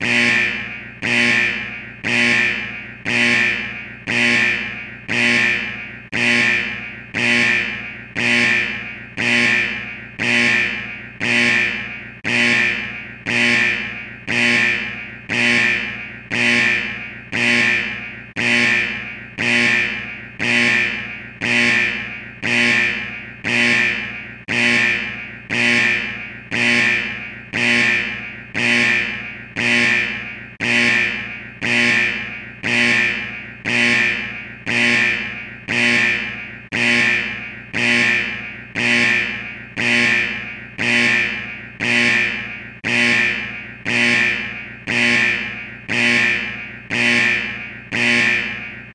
Som de Alerta Vermelho
Categoria: Sons de sinos e apitos
Ideal para quem deseja um alarme de emergência com efeito sonoro forte, ou um alerta de perigo com intensidade.
som-de-alerta-vermelho-pt-www_tiengdong_com.mp3